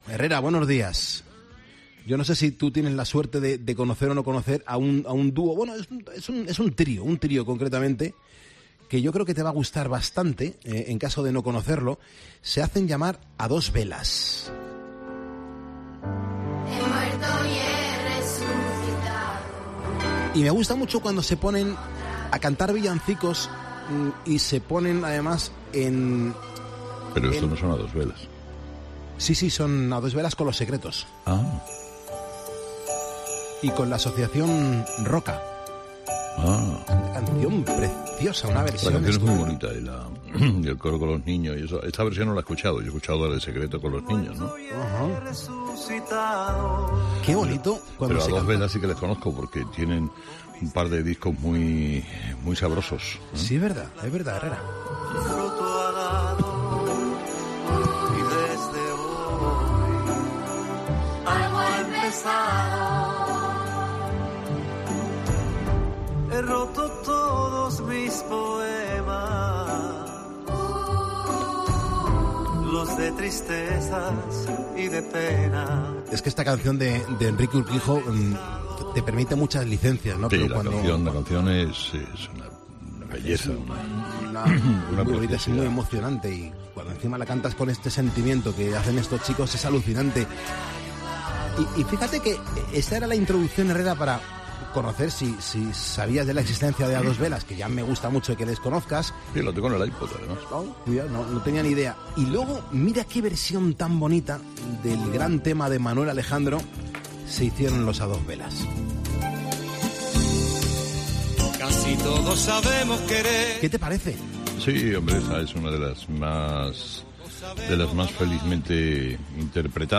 Música
Durante la conversación, han hecho referencia al buen gusto del grupo al elegir las canciones que interpretan en su repertorio y hemos tenido la oportunidad de escuchar algún fragmento del tema de los Secretos, Pero a tu lado, escrita por Enrique Urquijo.